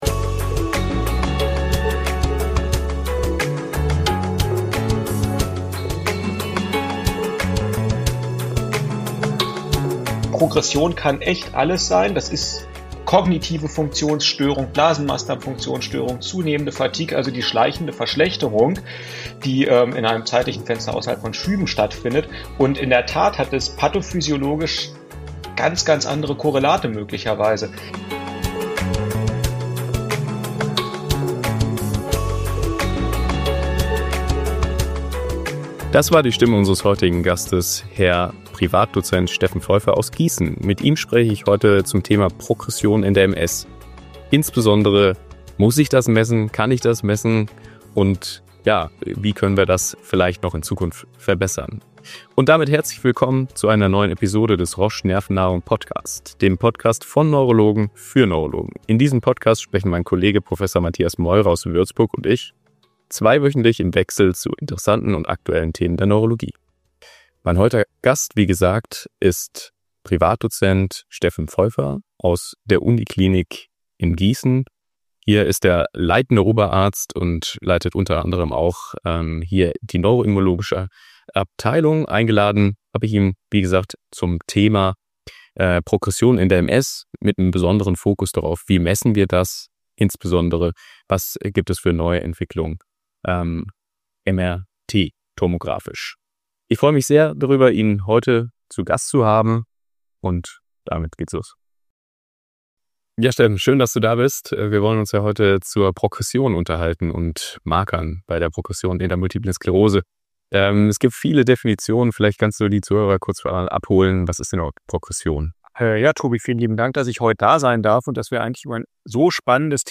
MS-Progression im Experten-Talk